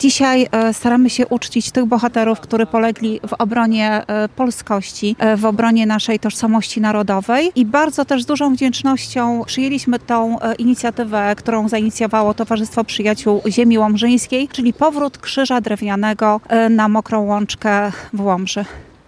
Głównym punktem uroczystości było podniesienie i poświęcenie krzyża na „Mokrej Łączce” – miejscu kaźni bohaterów Powstania.
Wicestarosta łomżyński Anna Gawrych nie ukrywała wdzięczności wobec inicjatorów powrotu krzyża na „Mokrą Łączkę”.